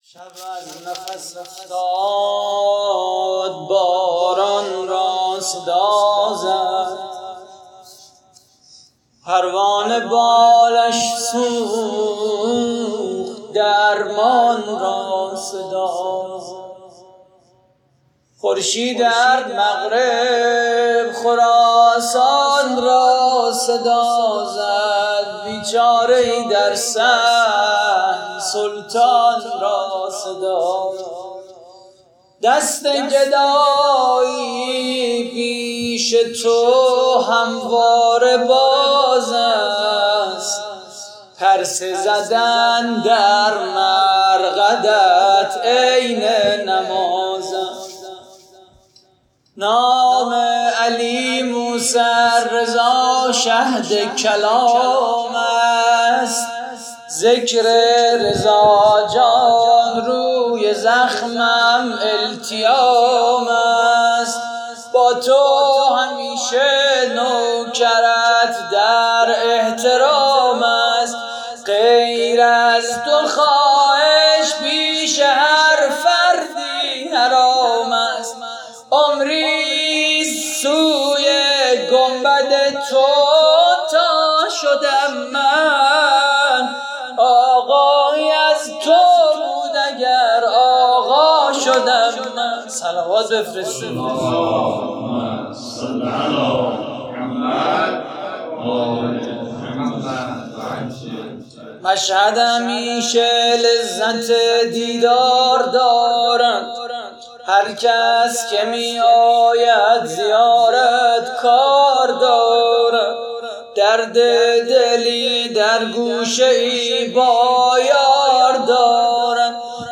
مدیحه خوانی: خورشیدِ هر مغرب، خراسان را صدا زد
مراسم هفتگی در دهه کرامت